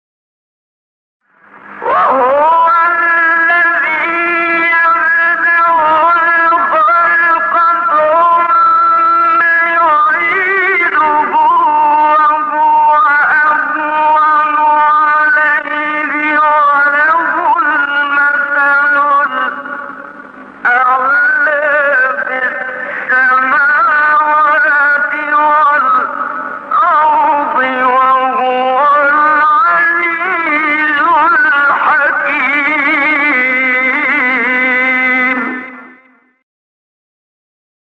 سایت قرآن کلام نورانی - نهاوند منشاوی (1).mp3
سایت-قرآن-کلام-نورانی-نهاوند-منشاوی-1.mp3